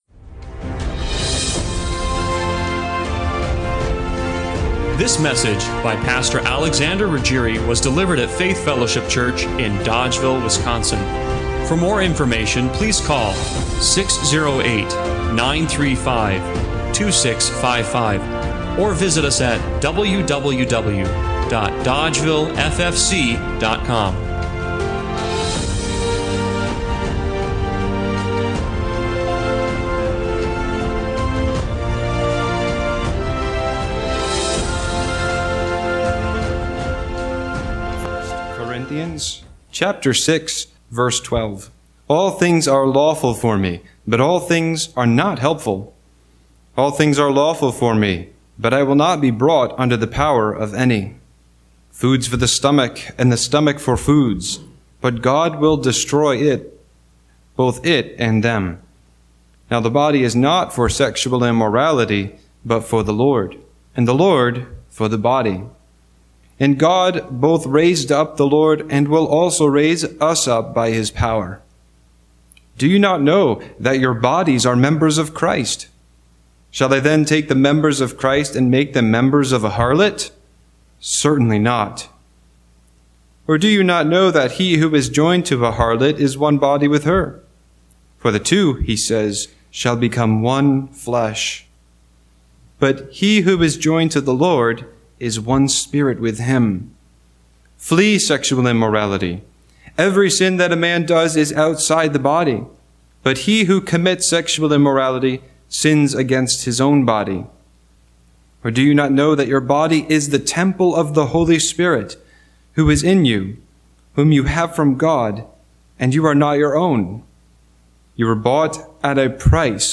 1 Corinthians 6:12-20 Service Type: Sunday Morning Worship Why does God care about our bodies so much?